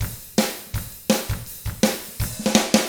164ROCK F5-L.wav